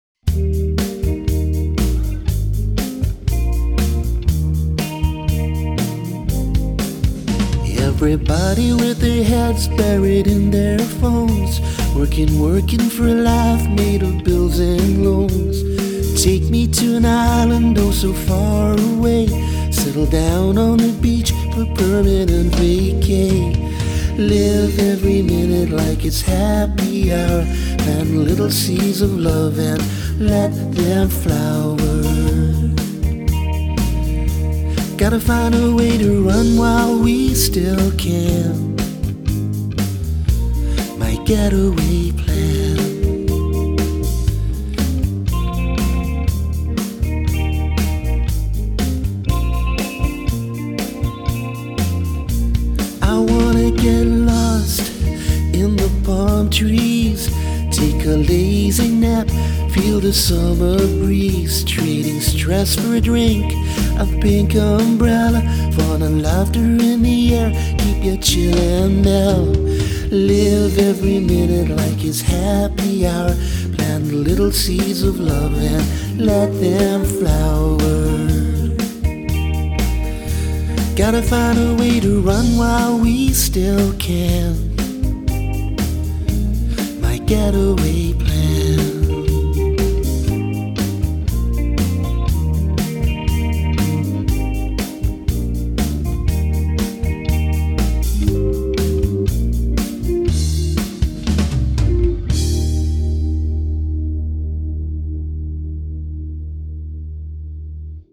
The instrumentation is mellow and perfectly paced.